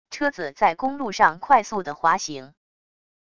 车子在公路上快速的滑行wav音频